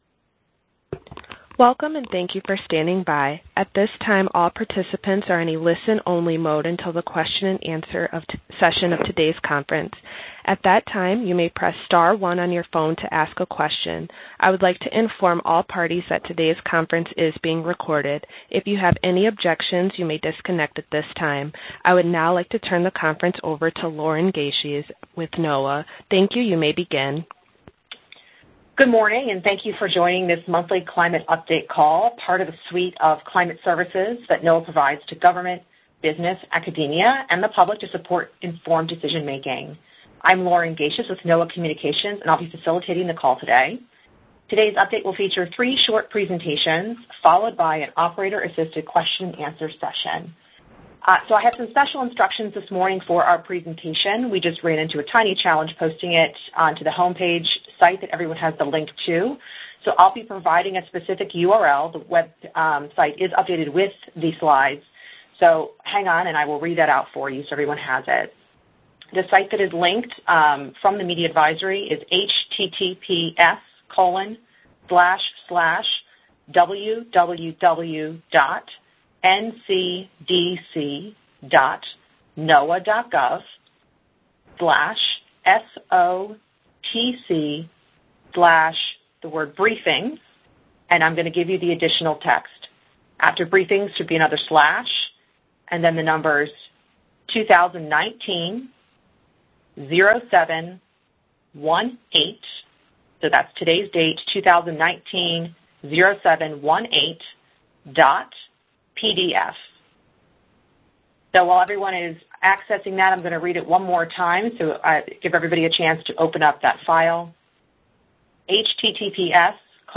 JulyClimateTelecon2019.mp3